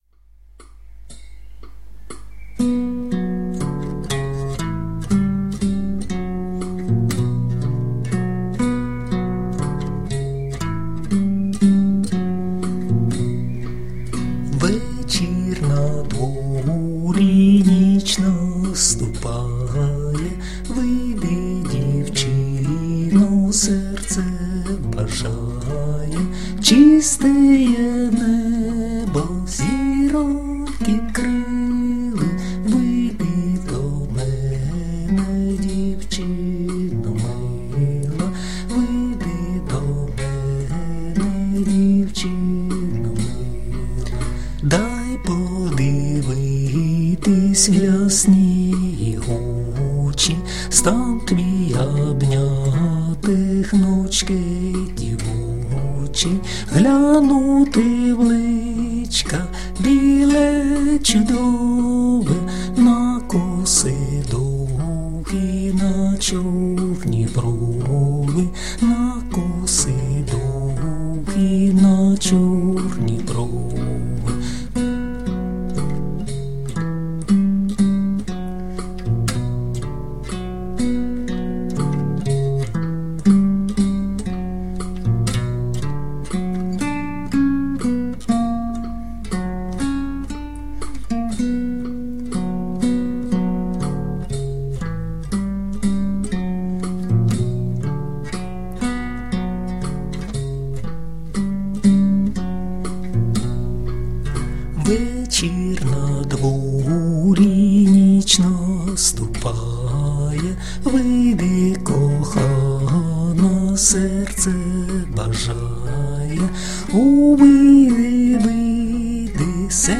../icons/vzyavbyb.jpg   Українська народна пісня